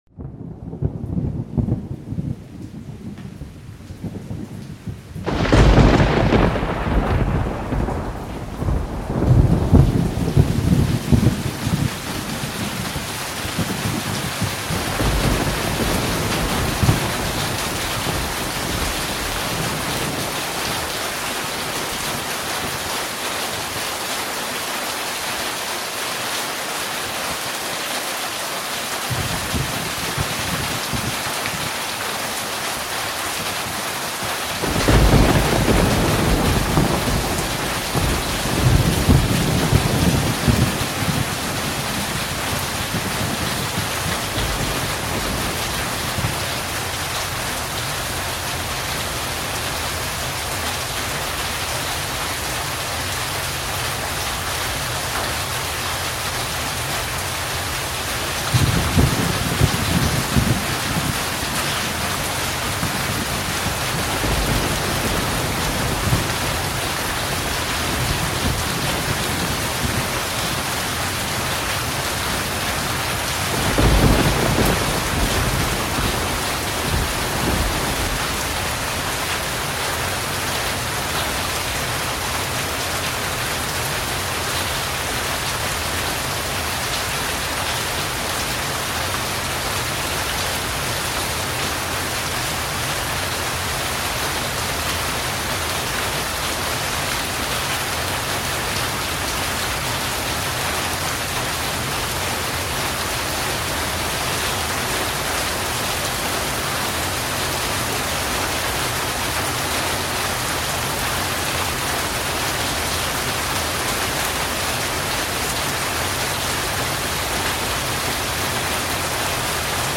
Relax To The Soothing Sounds Sound Effects Free Download